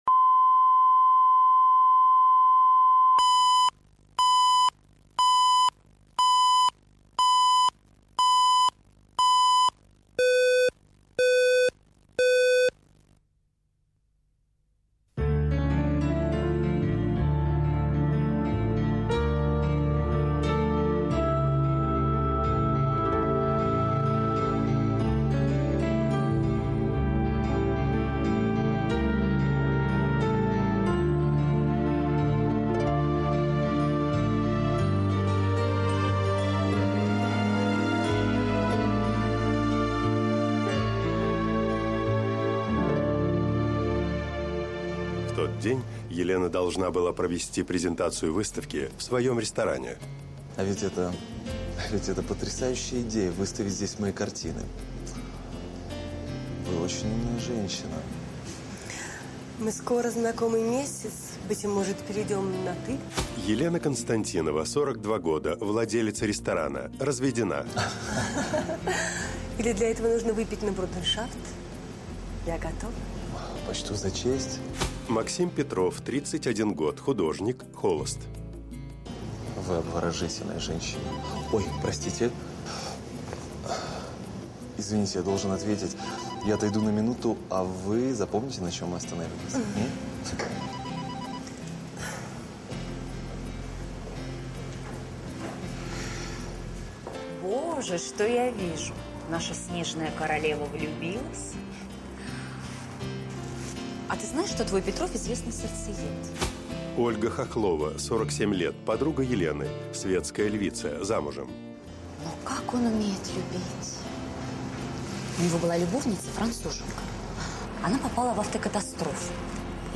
Аудиокнига Выкупи меня | Библиотека аудиокниг
Прослушать и бесплатно скачать фрагмент аудиокниги